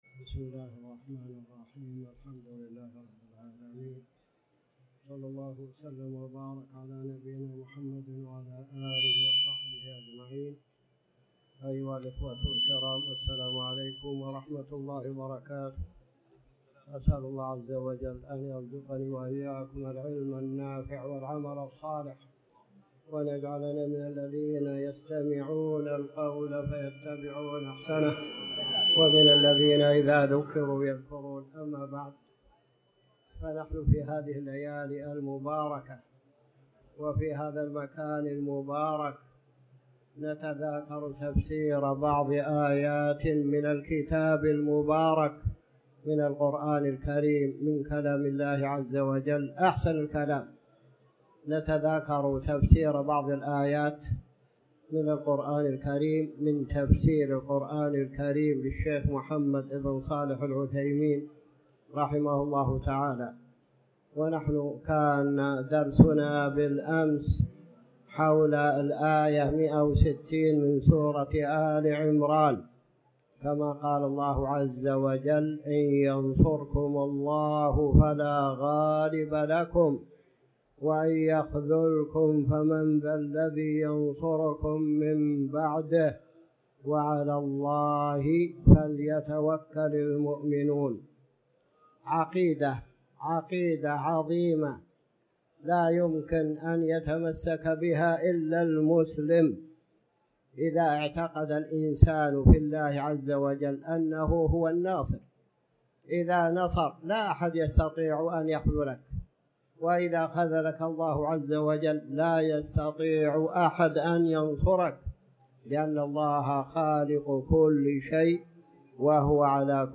تاريخ النشر ٥ ربيع الأول ١٤٤٠ هـ المكان: المسجد الحرام الشيخ